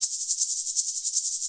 Shaker 01.wav